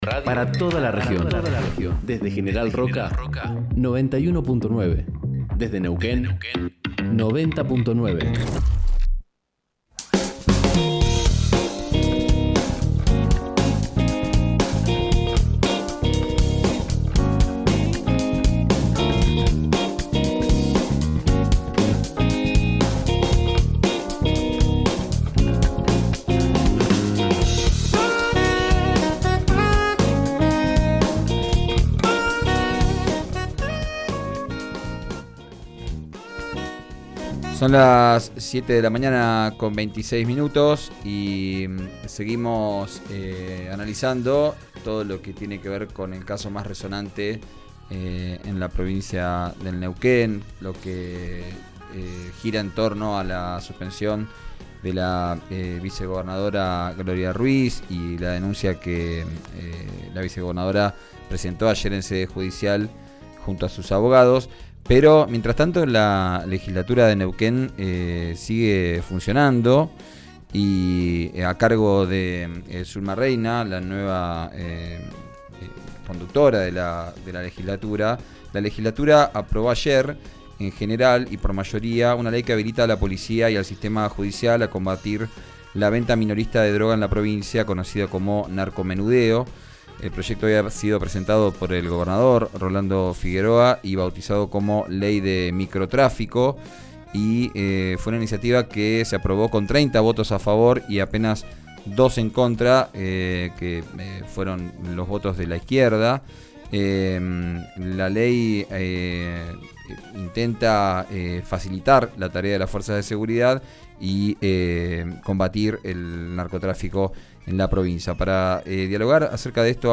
Escuchá a Ernesto Novoa, presidente del bloque oficialista, en RÍO NEGRO RADIO: